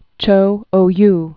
(chō ō-y)